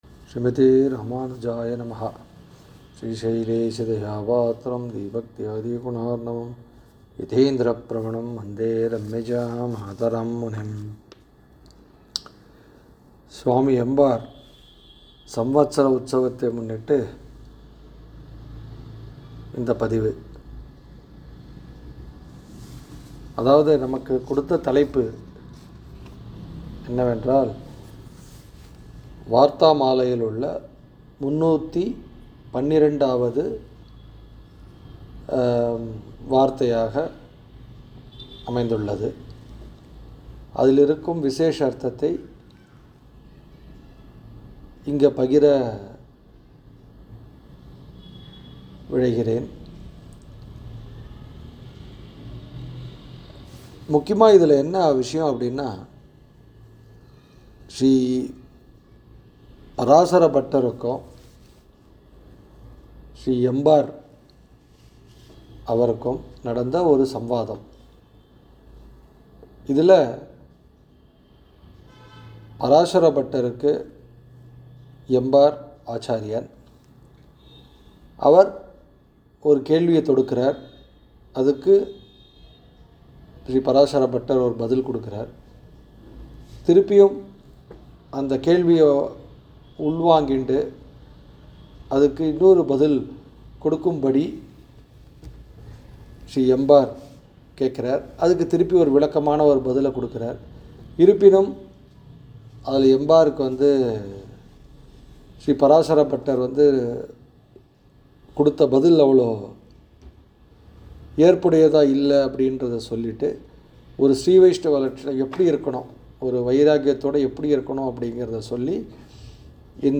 விஶ்வாவஸூ ௵ தை ௴ எம்பார் ஆயிரமாவது திருநக்ஷத்திர மஹோத்ஸவ உபன்யாஸ ஸமர்ப்பணம்*.